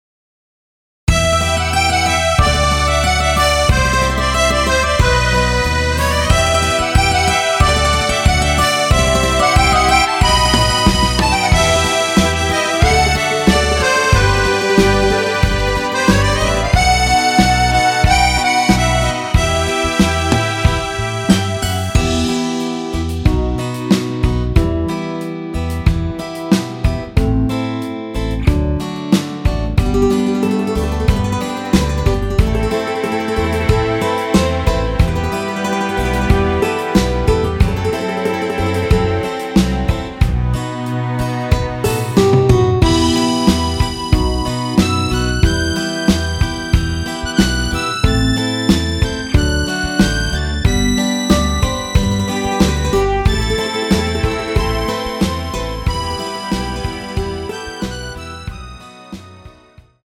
원키에서(+2)올린 MR입니다.
Em
앞부분30초, 뒷부분30초씩 편집해서 올려 드리고 있습니다.